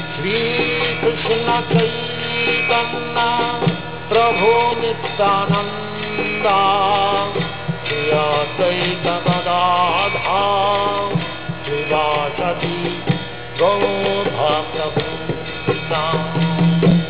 Presione aquí y escuche el pancatttwa maha-mantra (wav) cantado por Srila Bhaktivedanta Swami Prabhupada